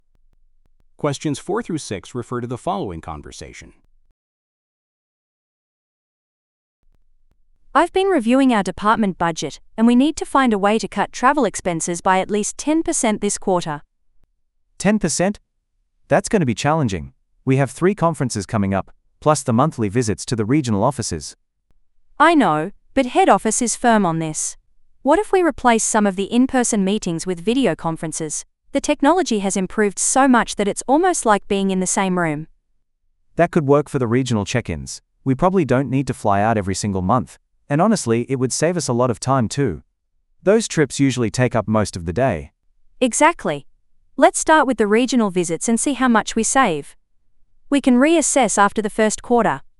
※TOEICは、アメリカ/イギリス/オーストラリア/カナダ発音で出ます。